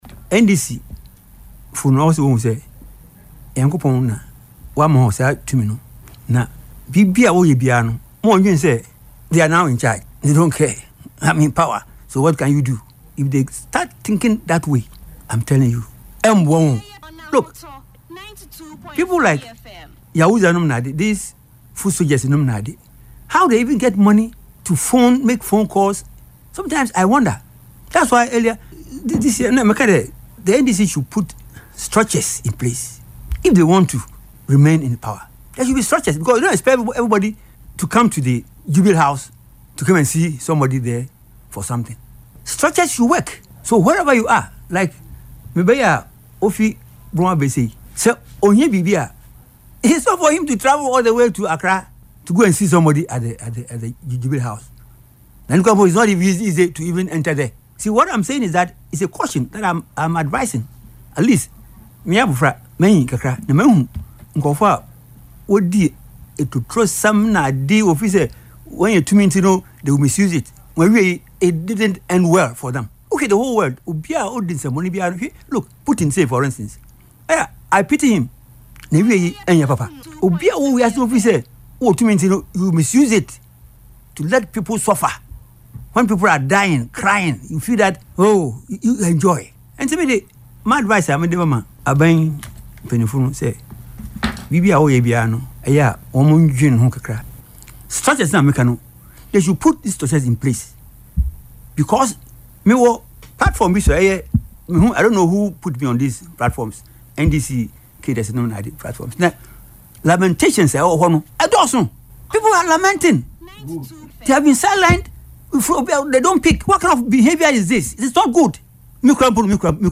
Speaking on Ahotor FM’s Yepe Ahunu programme on Saturday, December 20,